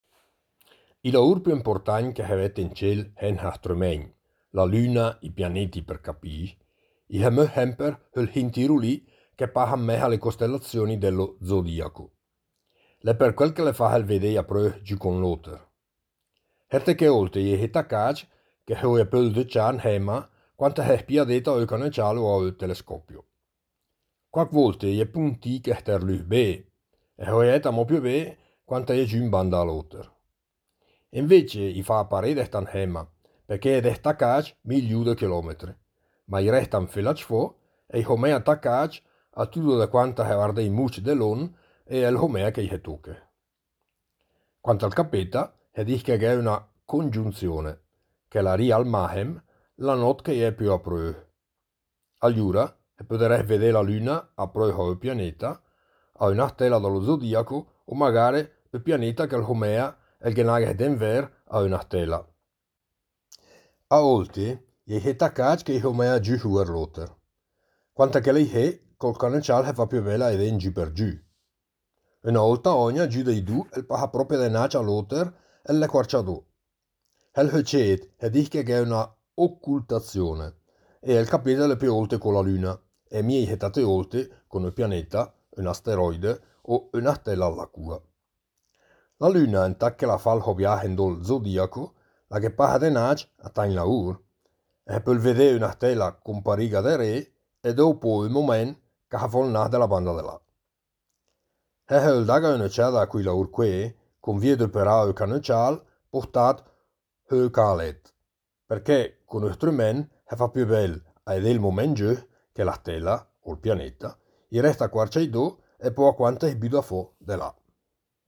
Il progetto “Idiomi celesti” promuove l’osservazione ad occhio nudo del cielo stellato con testi scritti e letti ad alta voce in lingua ladina e in dialetto lumezzanese.